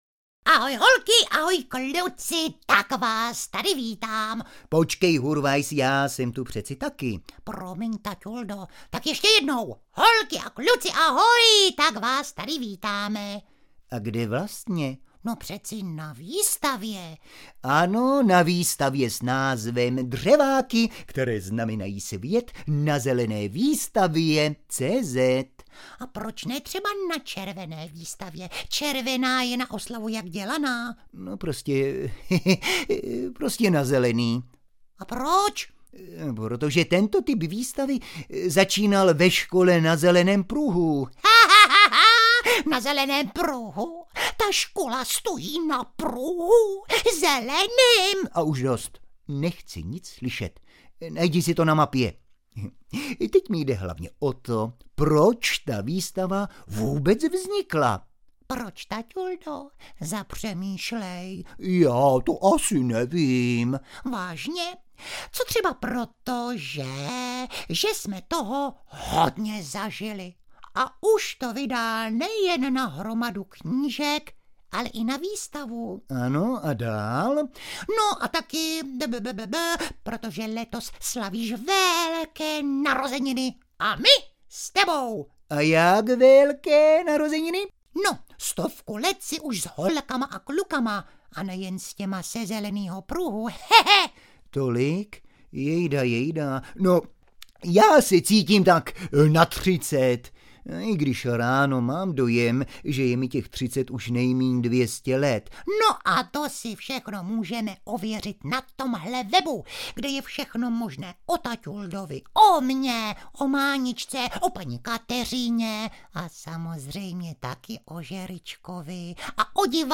Pokud se ti samo nespustí přivítání Spejbla a Hurvínka, klikni na šipku přehrávače v obrázku.